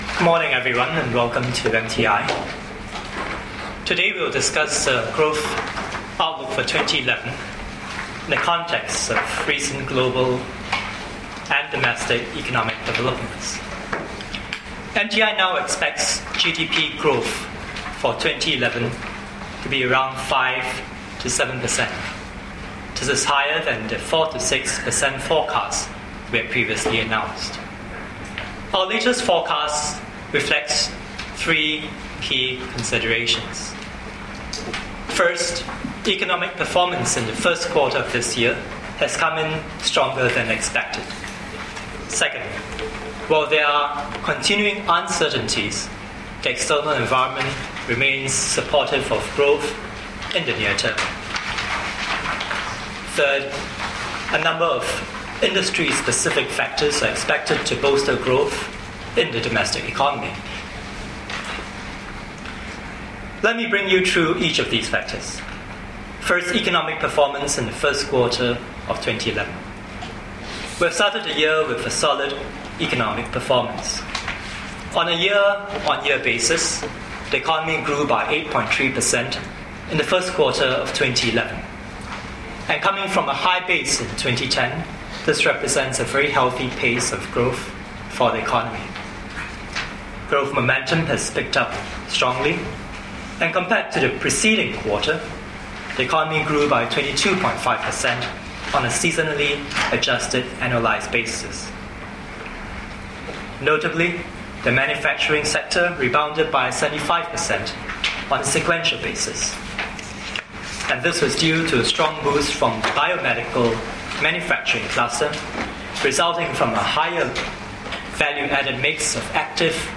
Mr Kwek Mean Luck's Opening Remarks at the Economic Survey Of Singapore (1Q11) Media Briefing, 19 May 2011